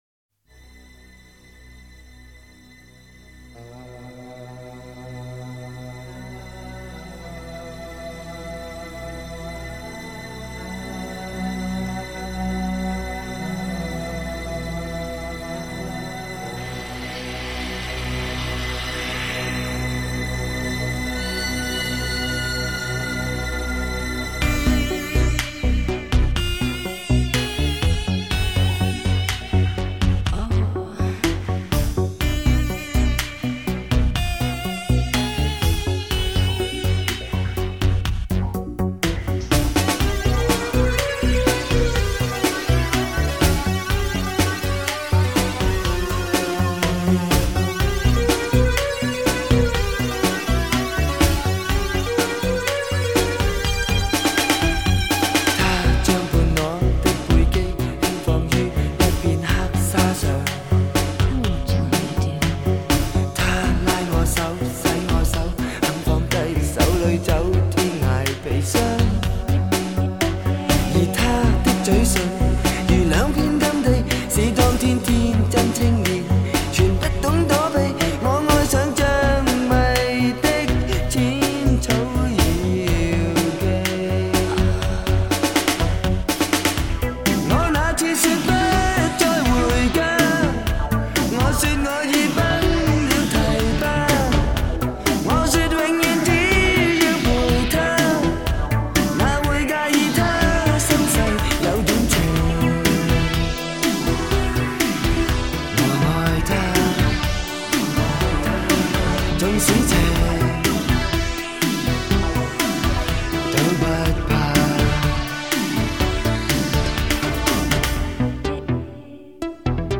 在他的歌里能感受到茫然和低落。
样本格式    : 44.100 Hz; 16 Bit; 立体声